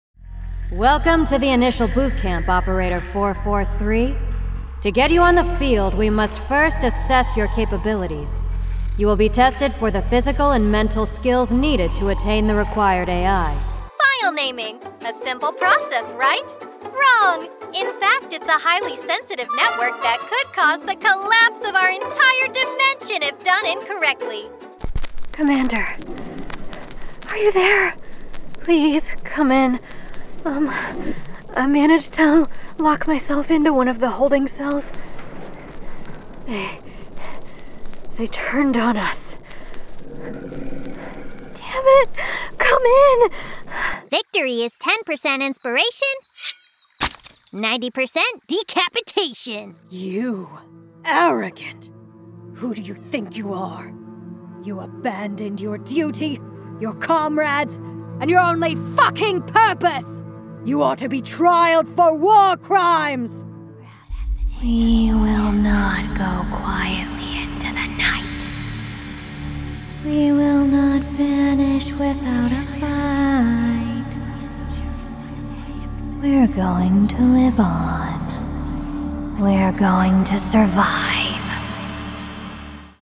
Video Games
I have a wonderfully weird, bouncy voice that has inspired many a collaborator to think about their works in entirely new lights.
-StudioBricks double-walled insulated vocal booth